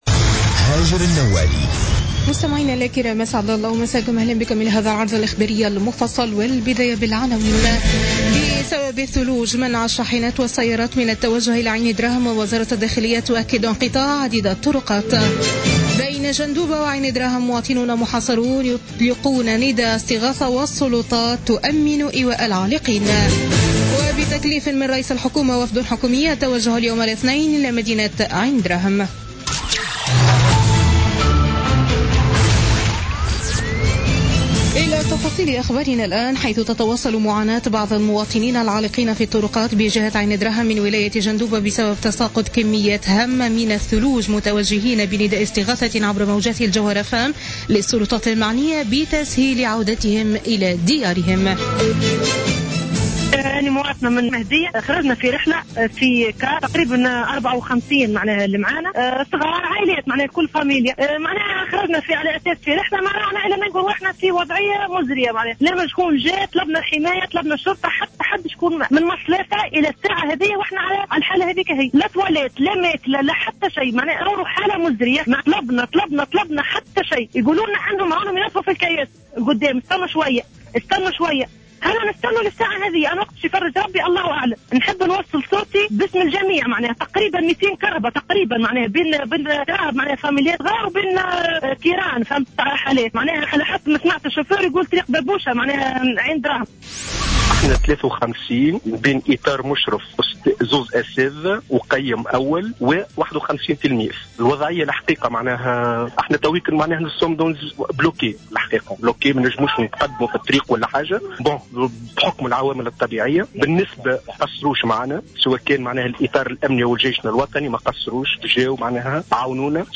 Journal Info 00h00 du lundi 16 Janvier 2017